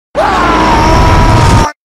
TF2 Demoman Charge sound 1
tf2-demoman-charge-sound-1.mp3